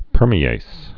(pûrmē-ās)